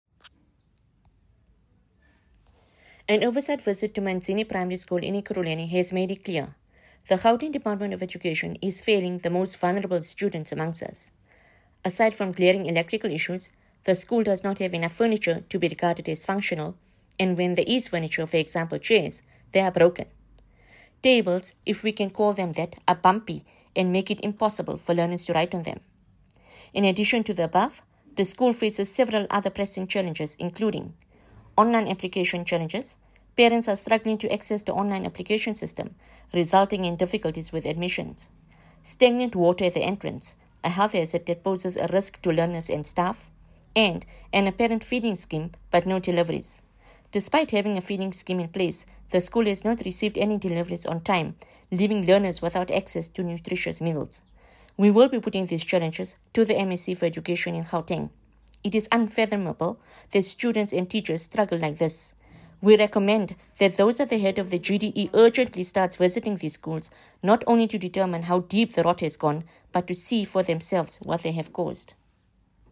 Note to Editors: Please find an English soundbite by Haseena Ismail MP,
Haseena-Ismail-MP_ENG_Manzini-Primary-School-1.mp3